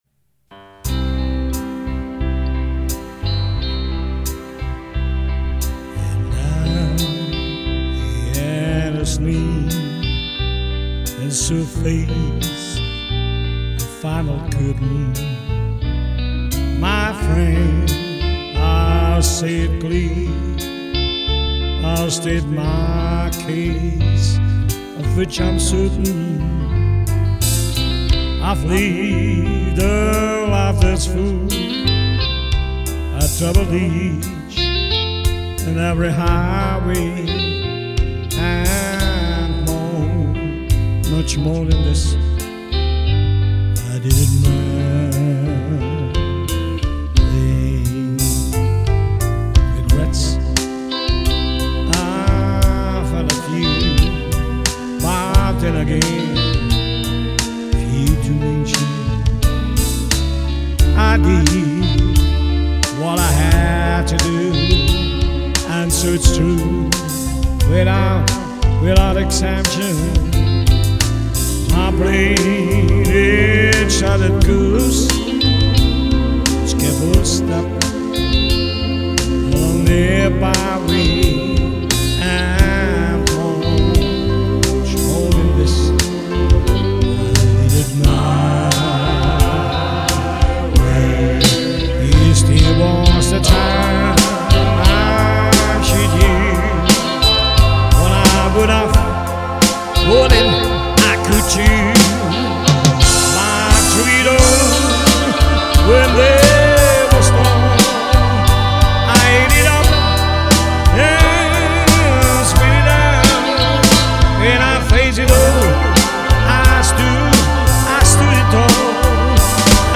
Schmusesongs